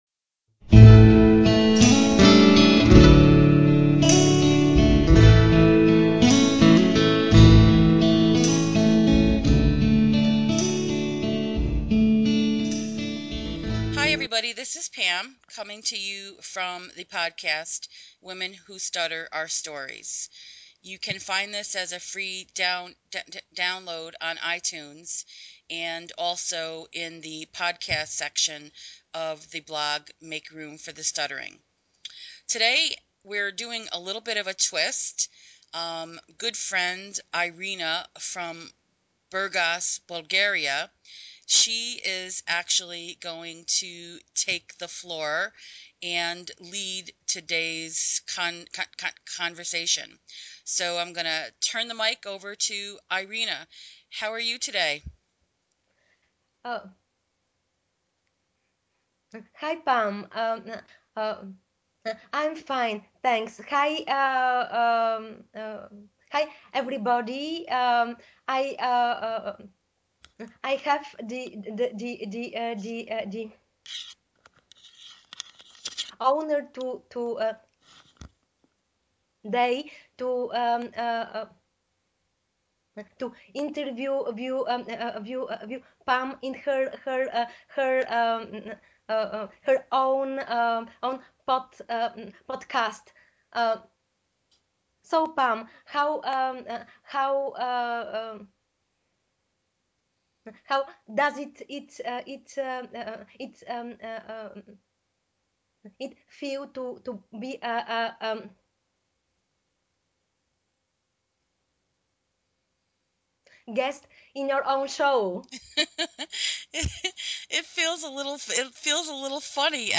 We had to do the Skype session twice, because the first time we had such a poor connection between New York, USA and Burgas, Bulgaria.